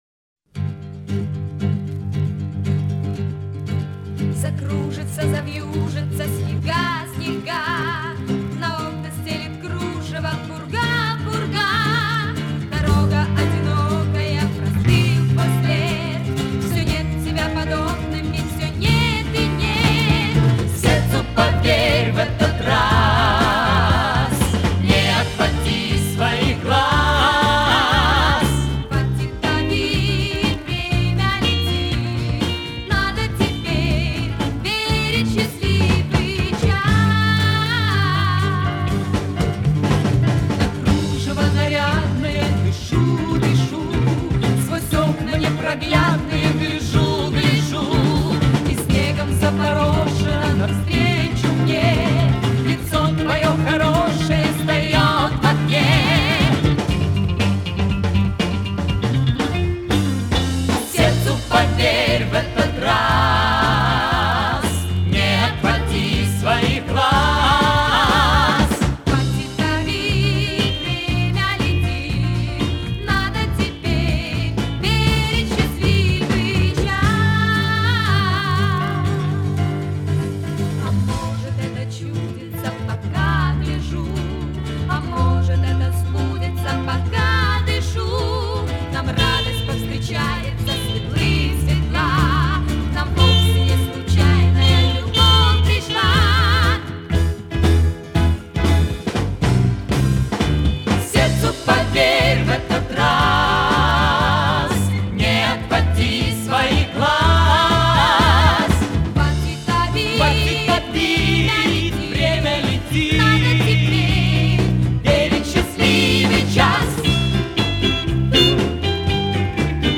на слух хорошее качество .